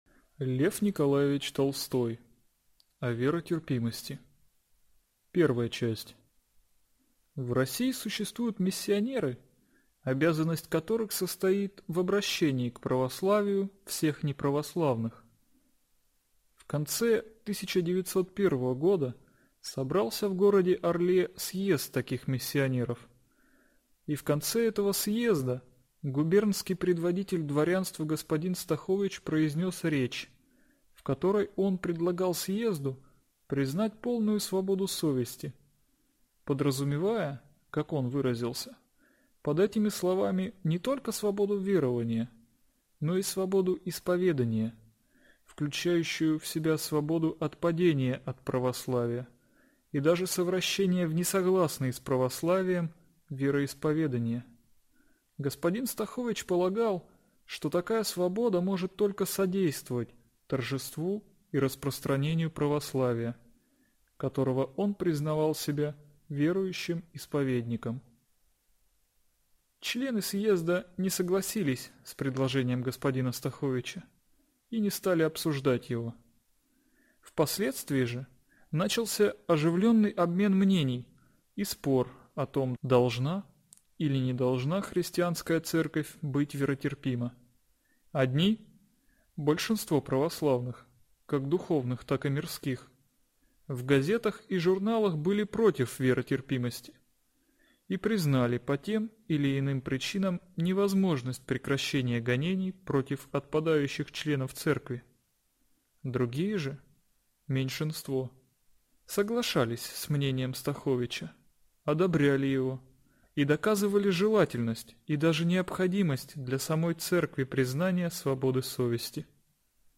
Аудиокнига О веротерпимости | Библиотека аудиокниг